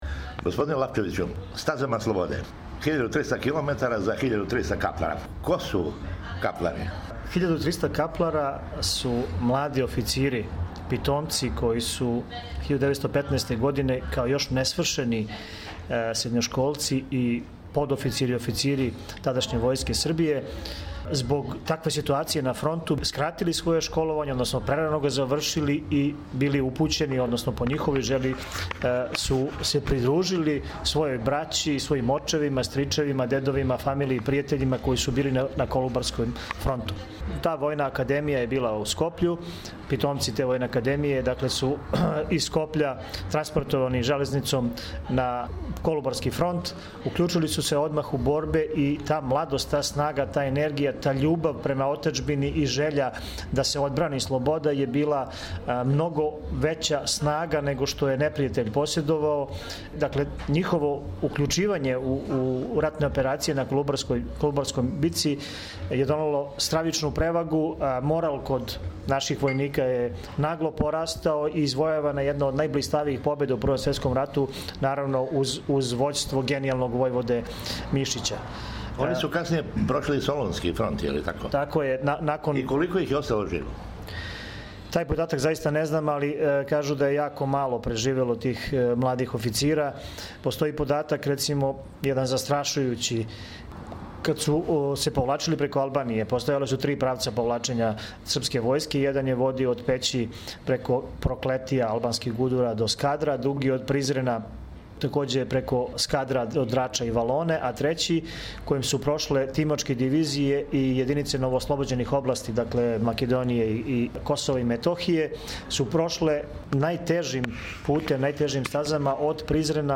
је о овом јединственом маратону разговарао са Миланом Лапчевићем, послаником у српском парламенту и председником нишког Друштва за неговање историје и традиције „Стазама славних предака“, који ће иначе предводити бициклисте на стази дугој 1300 километара.